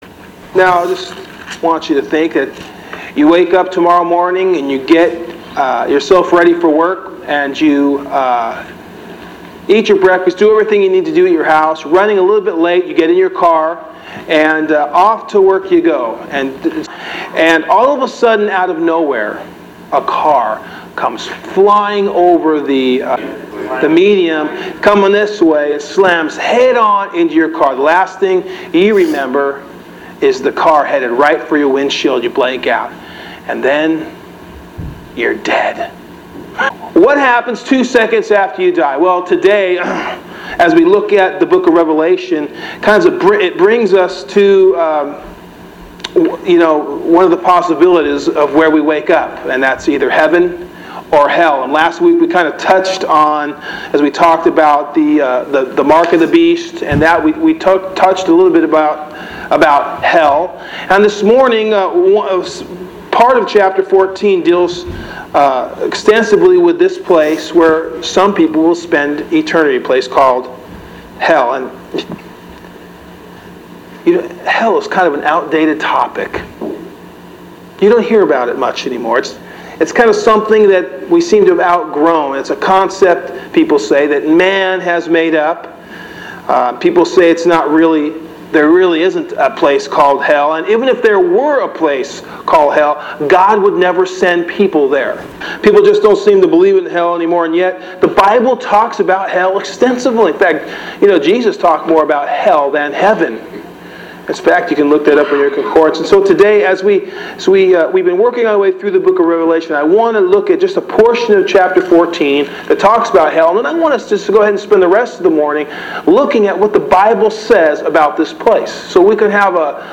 A Bible Study on the Biblical topic of Hell based on Revelation chapter 14.